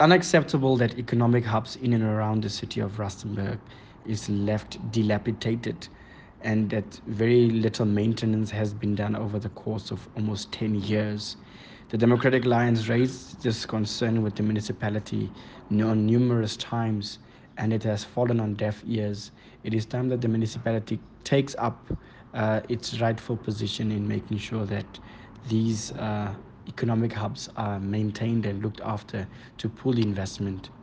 Issued by Cllr Luan Snyders – DA Councillor: Rustenburg Local Municipality
Note to Editors: Please find the attached soundbite in